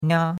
nia1.mp3